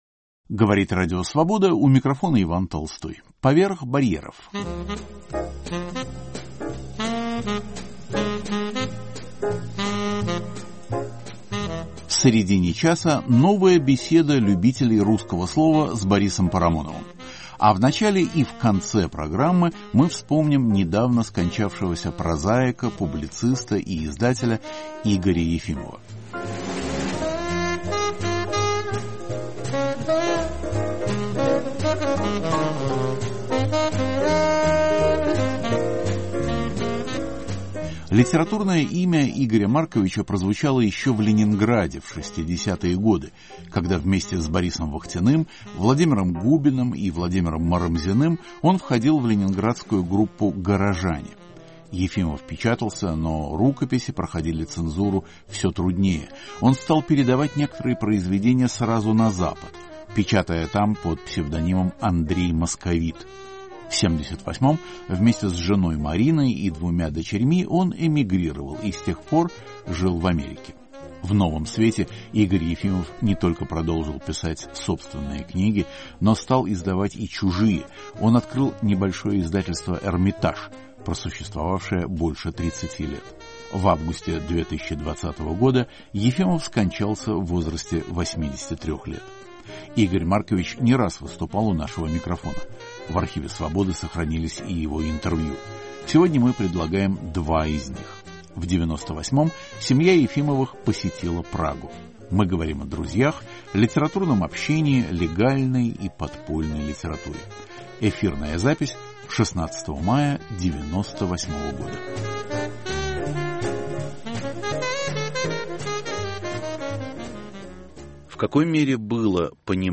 Новая беседа любителей русского слова с Борисом Парамоновым. Наша тема - поэзия и философия Дениса Новикова.
Архивные пленки.